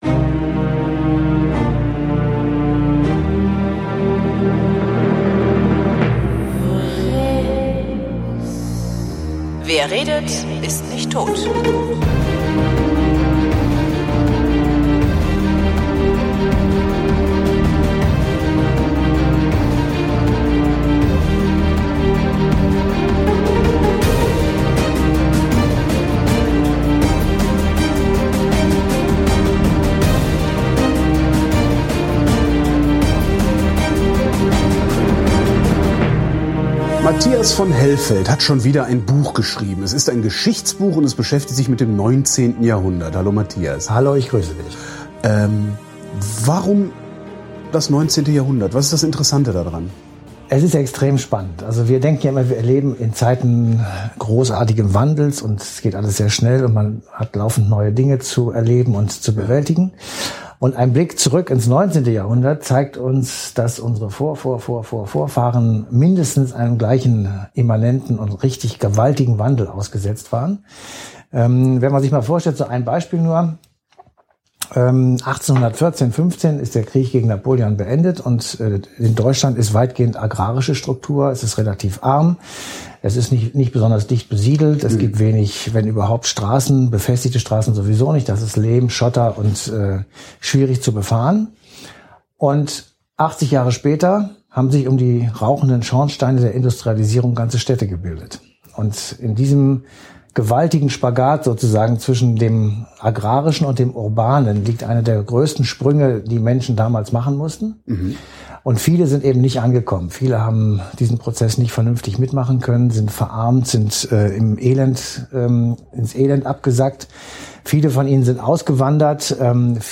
Ich habe mit ihm darüber geredet.